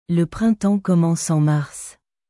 Le printemps commence en marsル プランタォン コマォンス オン マァルス